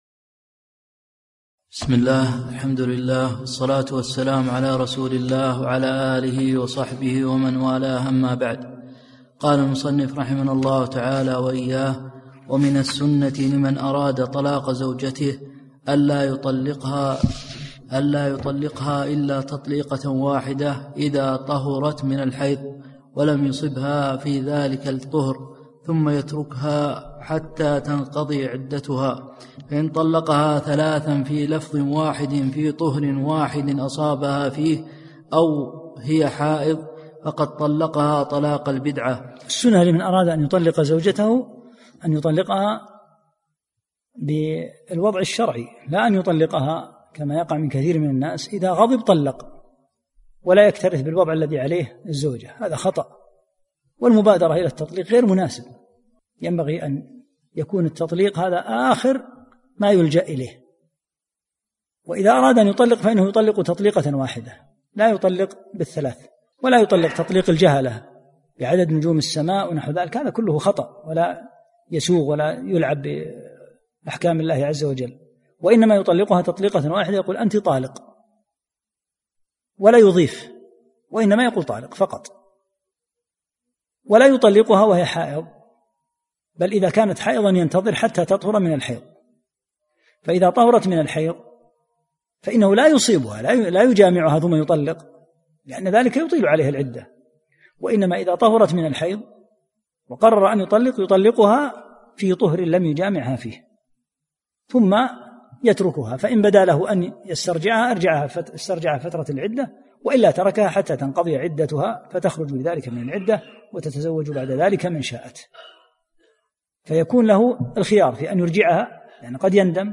44- الدرس الرابع والأربعون